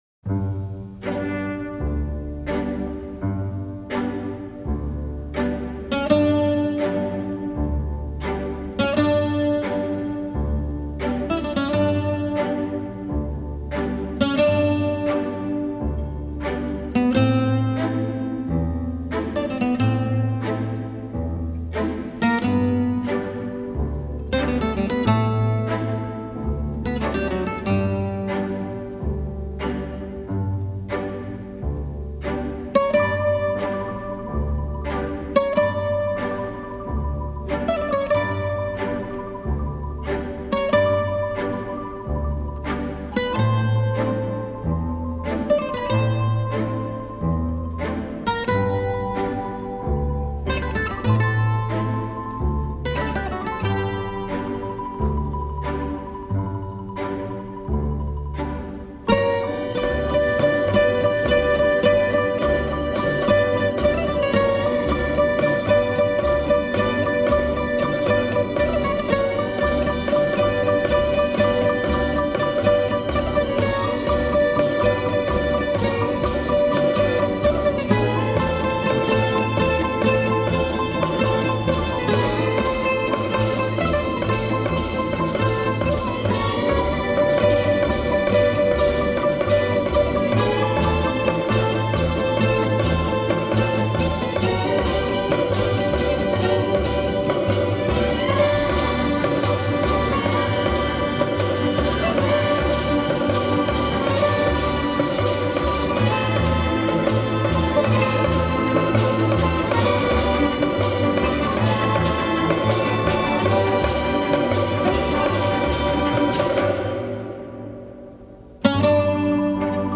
Жанр: Instrumental, Easy Listening, Soundtrack